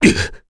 Bernheim-Vox_Damage_kr_01.wav